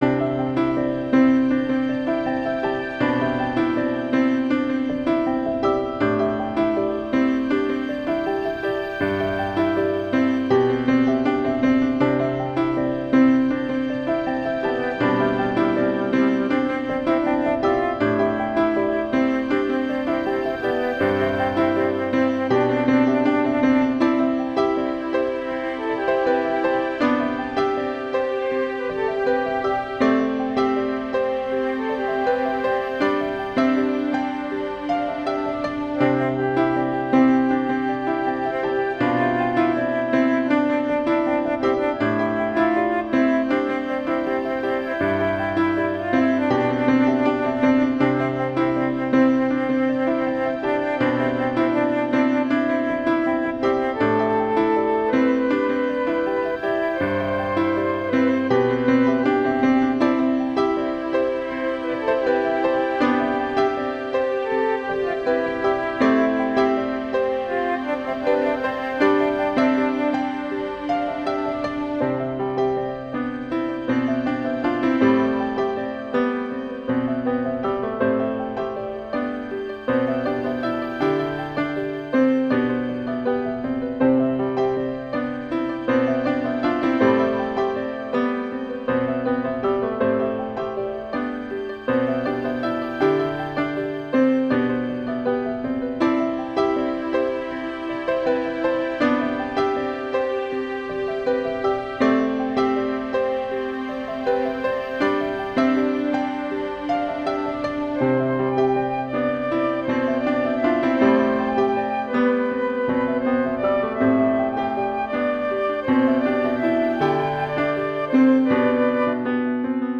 relaxing, piano
Emotional orchestral music